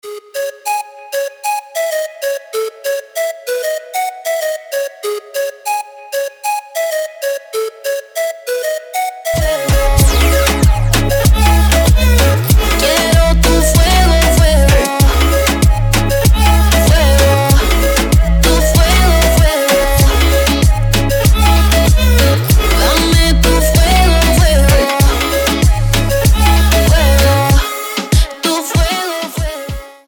• Качество: 320, Stereo
заводные
нарастающие
испанские
духовые
Reggaeton
Реггетон норм такой, начало в духе коко джамбо